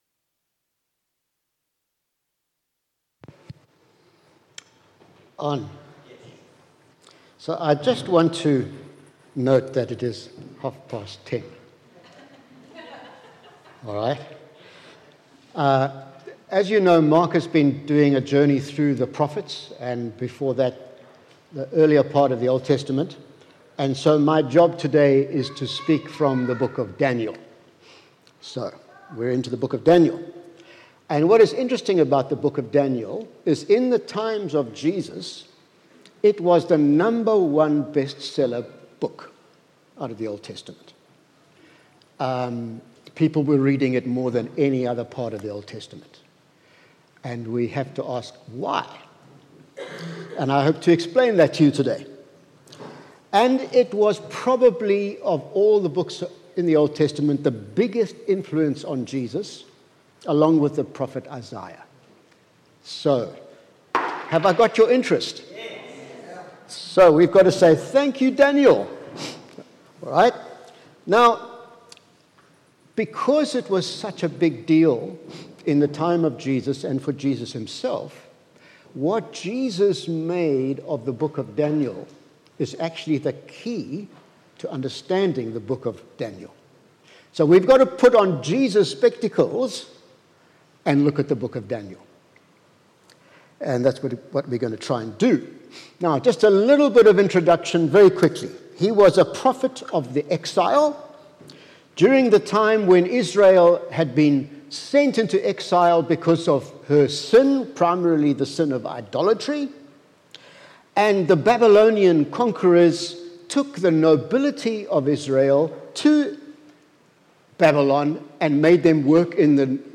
Sunday Service – 6 November
Sermons